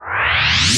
VEC3 Reverse FX
VEC3 FX Reverse 04.wav